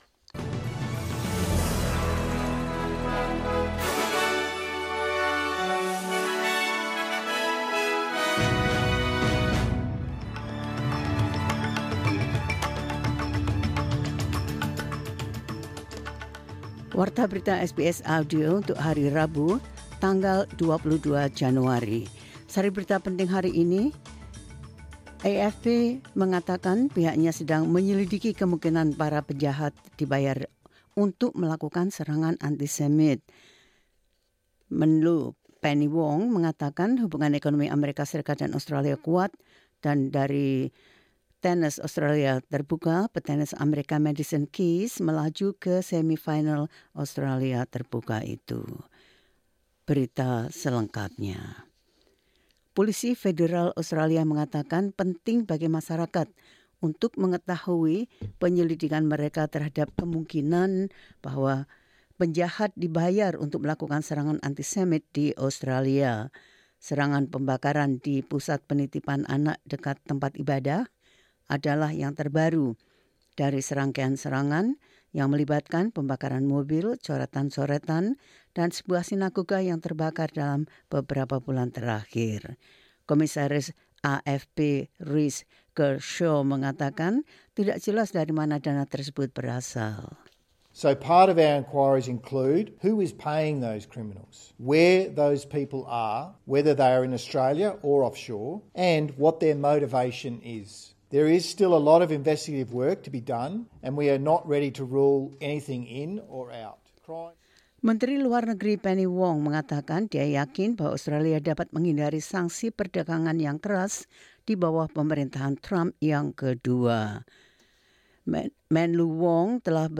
Berita terkini SBS Audio Program Bahasa Indonesia – 22 Januari 2025